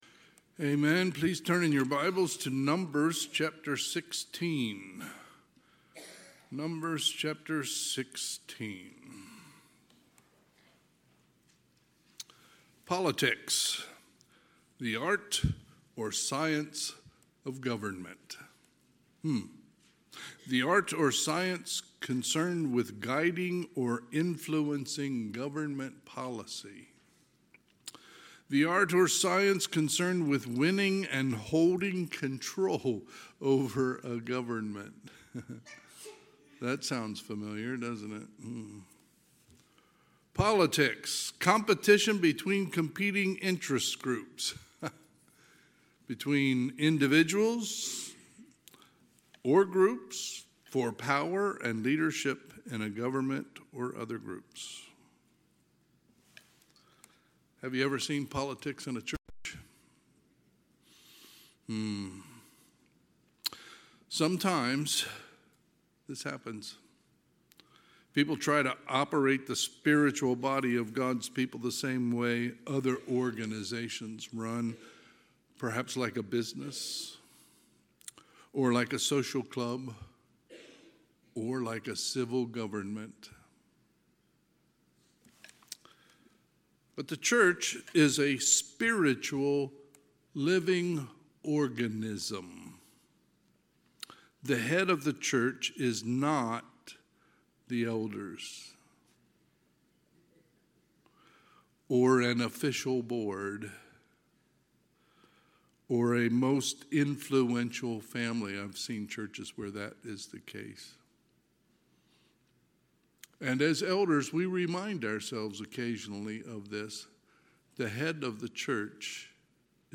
Sunday, March 23, 2025 – Sunday PM